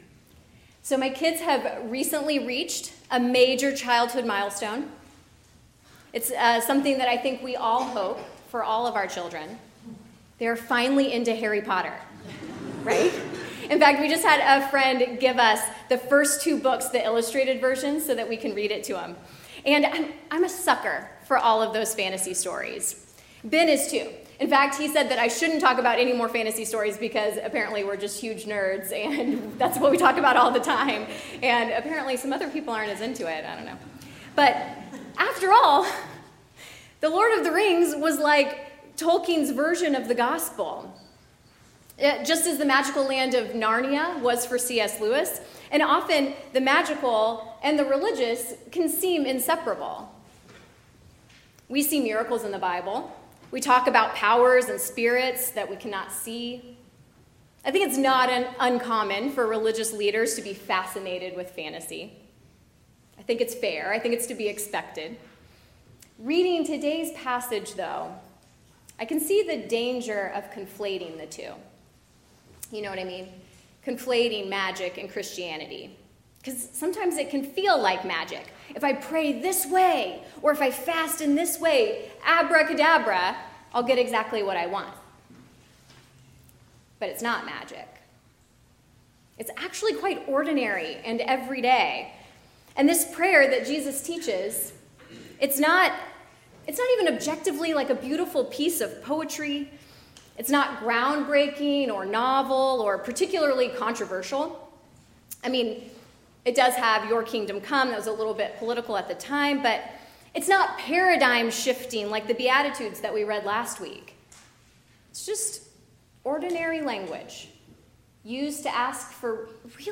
Gospel Lesson